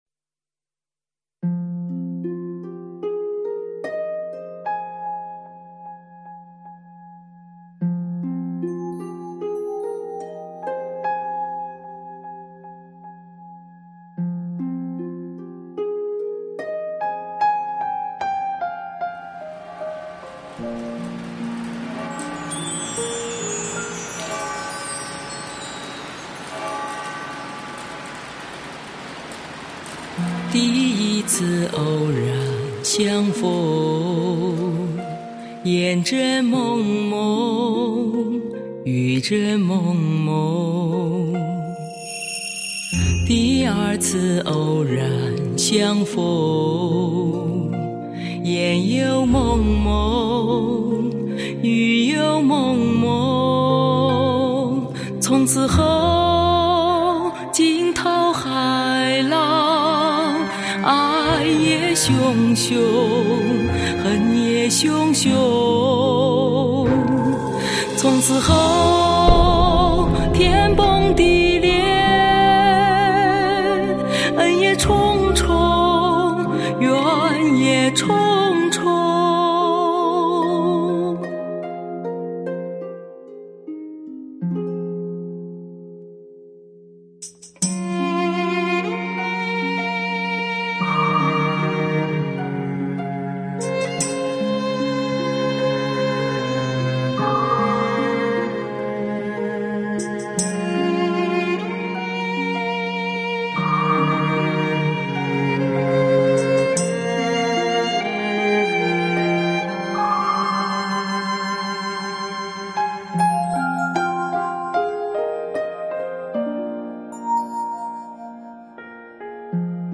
发烧/试音
最耐听的磁性嗓音匹配最经典的歌曲，质感深沉，极尽优美婉转；